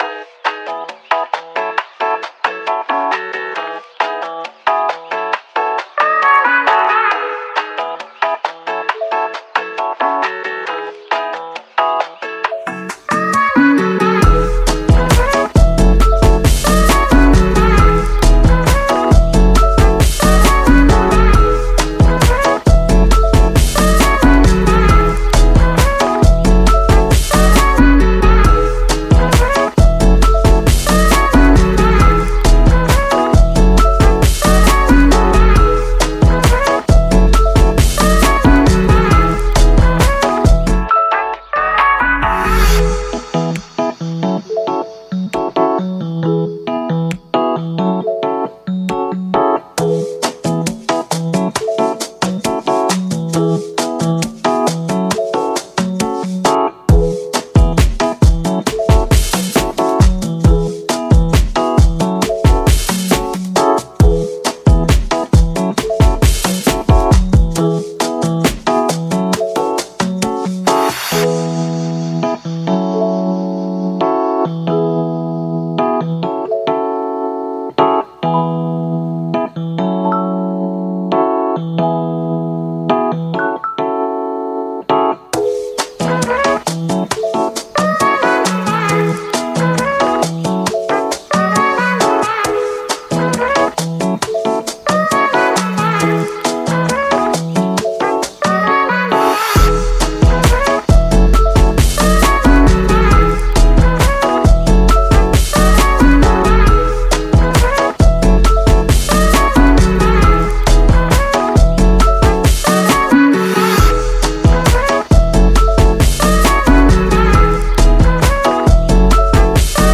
BPM135
MP3 QualityMusic Cut